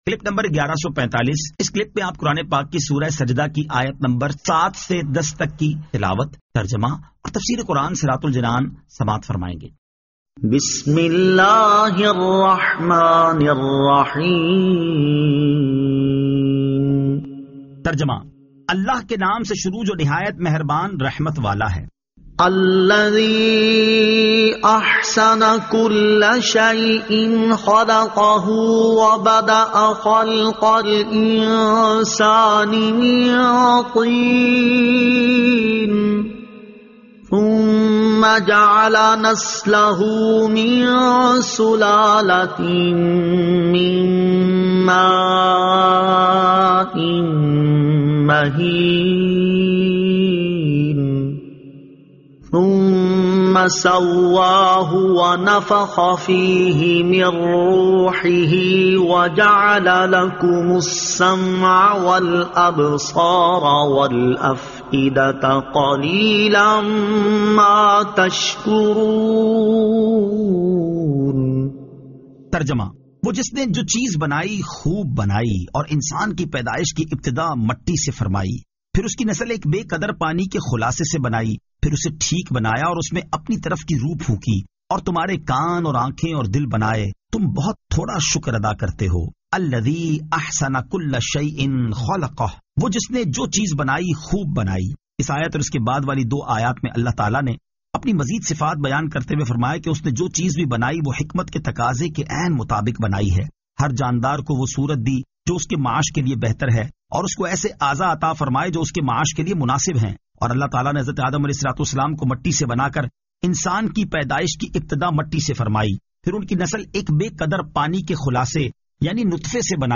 Surah As-Sajda 07 To 10 Tilawat , Tarjama , Tafseer